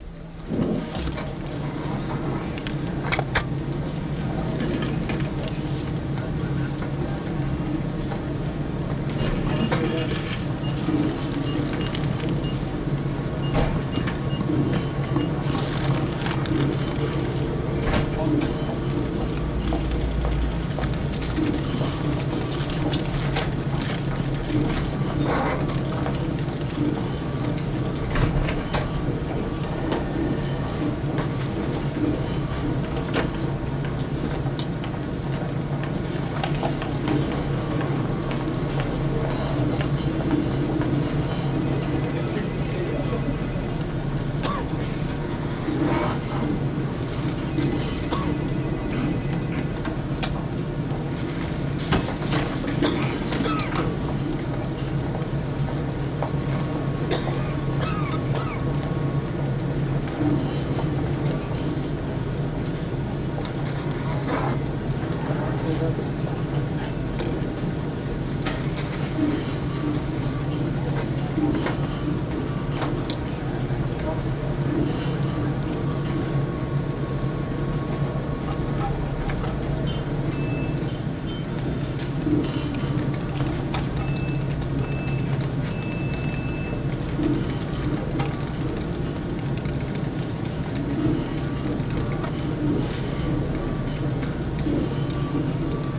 Testschalle / Loud Office_2020_10_22_Open Space Reaper Office.wav